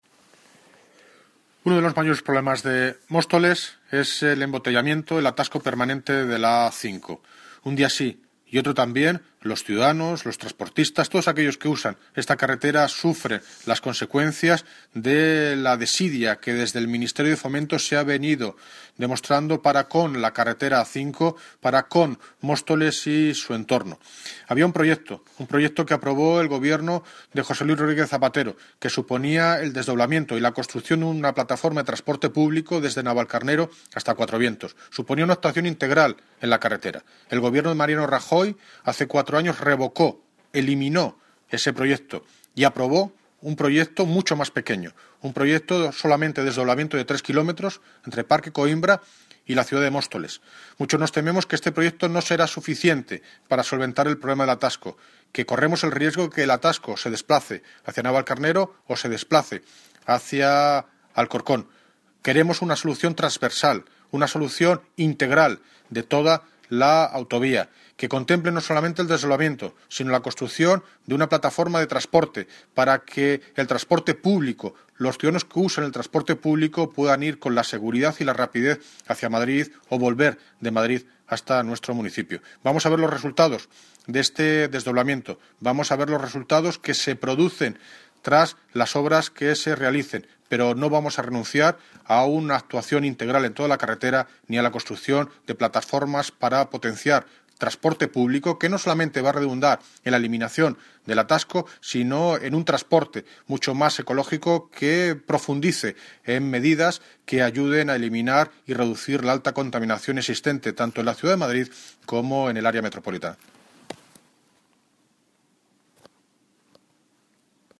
Audio - David Lucas (Alcalde de Móstoles) sobre obras A5
Audio - David Lucas (Alcalde de Móstoles) sobre obras A5.mp3